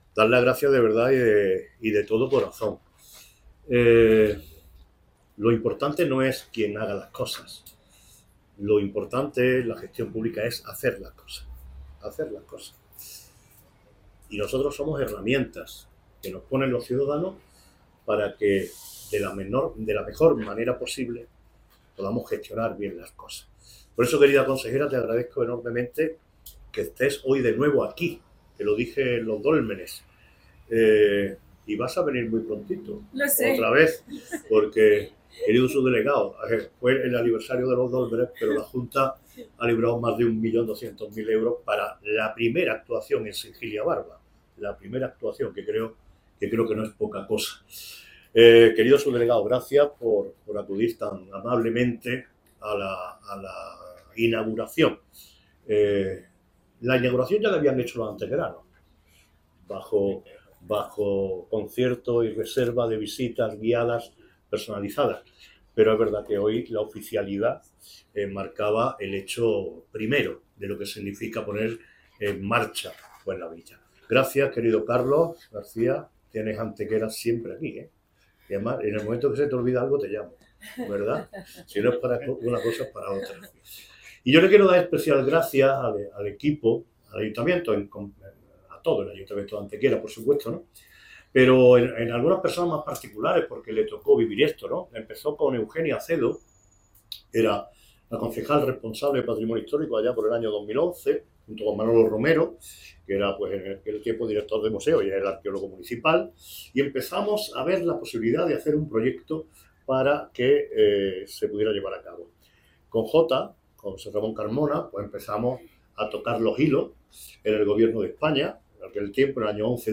El alcalde Manolo Barón ha presidido este mediodía la inauguración oficial de la Villa Romana de la Estación, acompañado por la consejera de Cultura de la Junta de Andalucía, Patricia del Pozo, y el subdelegado del Gobierno de España en Málaga, Francisco Javier Salas.
Cortes de voz